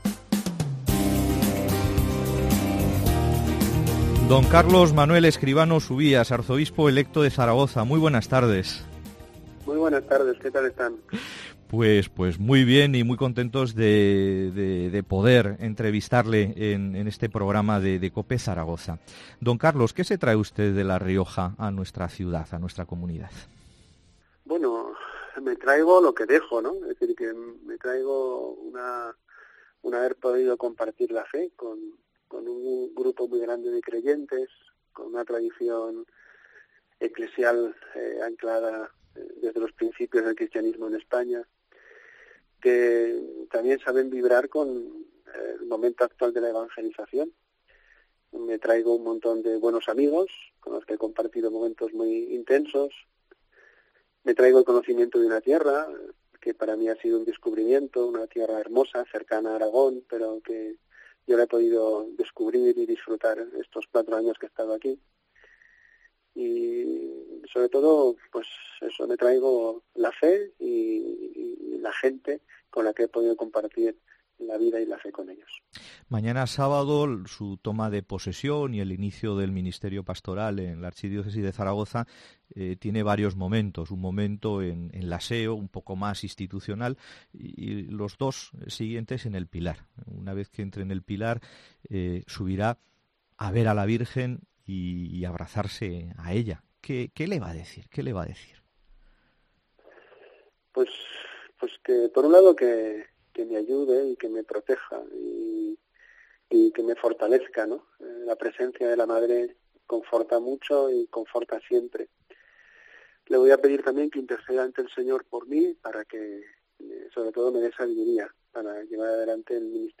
Entrevista a Don Carlos Escribano, nuevo Arzobispo de Zaragoza.